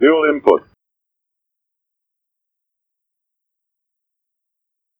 A320-family/Sounds/Cockpit/dual-input.wav at 783e06b86c77c931e7dde76e2b7d52b2a09dd7fc
dual-input.wav